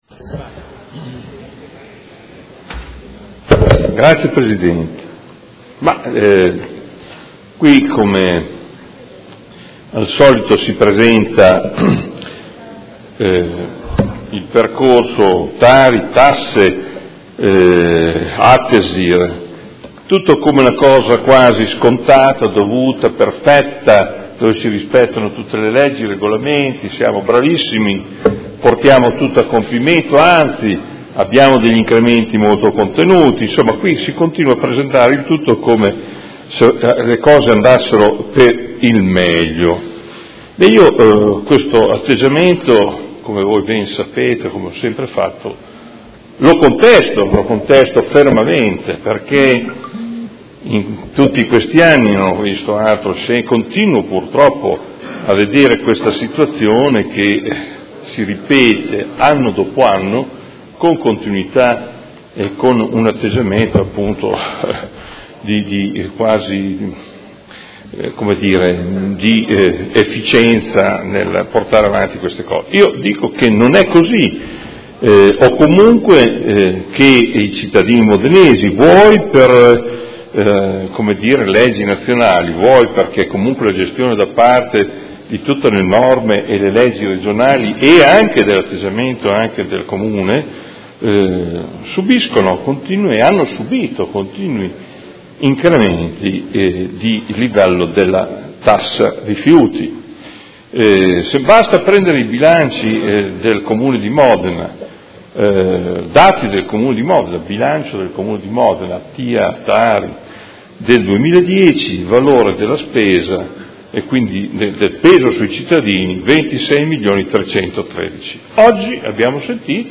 Seduta del 30/03/2017. Dibattito su proposta di deliberazione: Tassa sui rifiuti (TARI) anno 2017: Approvazione del Piano Economico Finanziario, delle tariffe, del piano annuale delle attività per l’espletamento dei servizi di gestione dei rifiuti urbani e assimilati e Ordine del Giorno presentato dai Consiglieri Arletti, Baracchi, Bortolamasi, Forghieri, Lentini, Carpentieri, Pacchioni, Liotti, De Lillo, Venturelli (PD), Malferrari, Cugusi e Rocco (Art.1-MDP) avente per oggetto: TARI 2017 uno strumento per il raggiungimento degli obiettivi ambientali fissati dalla Regione Emilia-Romagna al 2020